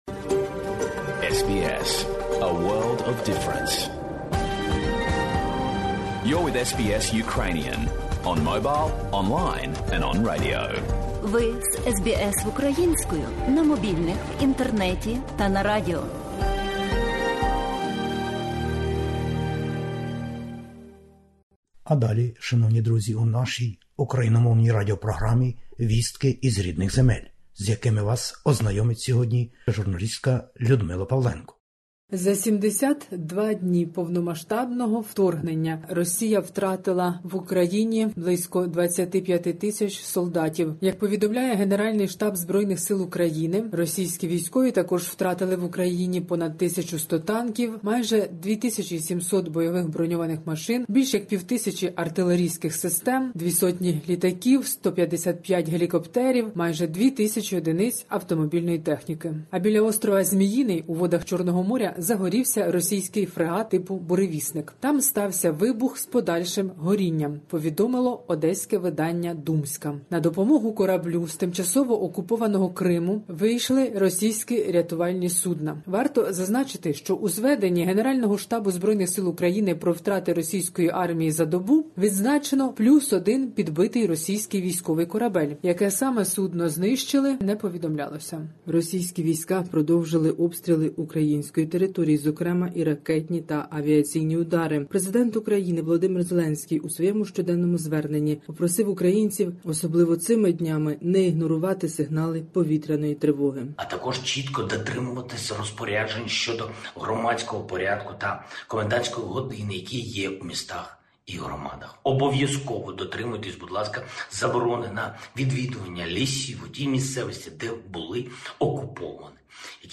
Добірка новин із героїчної України-неньки. Російські війська у війні з Україною втратили майже 25 000 військовиків на території України та чимало зброї і техніки.